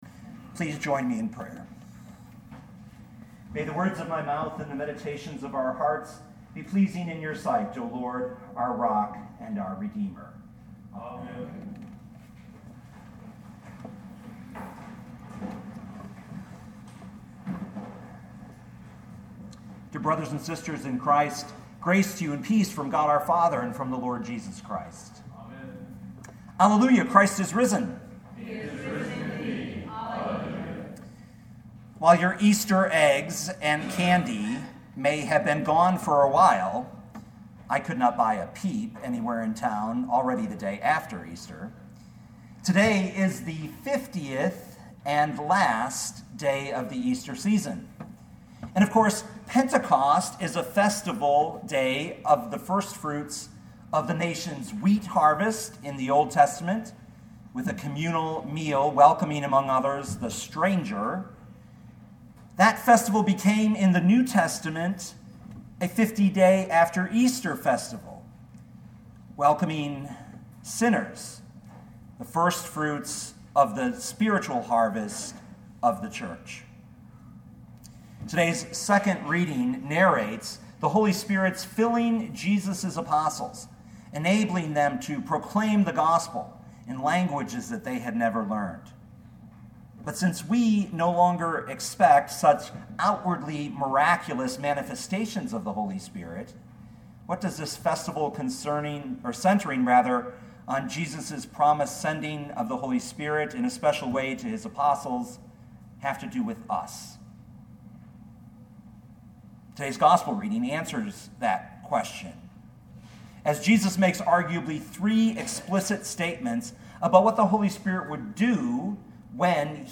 16:4b-15 Listen to the sermon with the player below, or, download the audio.